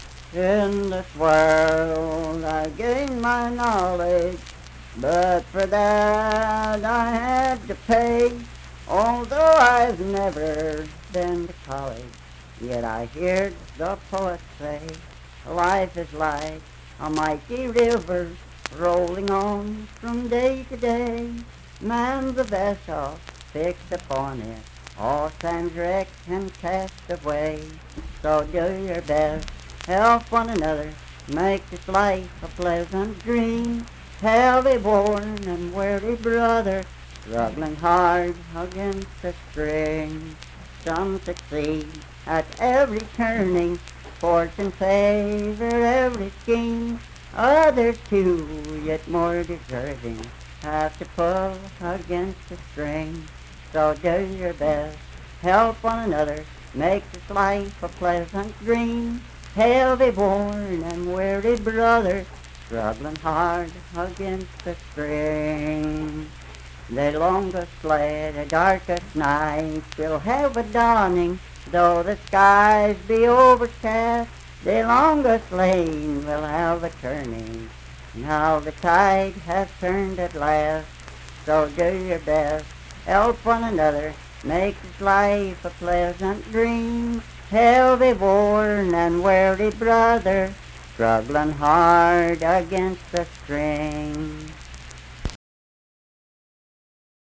Unaccompanied vocal music performance
Verse-refrain 4(4) & R(4).
Voice (sung)